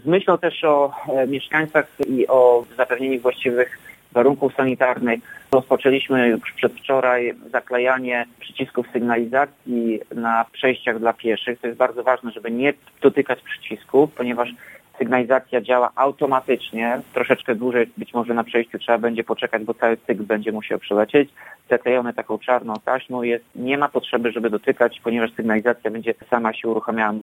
Jak wyjaśnia Tomasz Andrukiewicz, prezydent Ełku, na czas epidemii wprowadzono automatyczne sterowanie ruchem.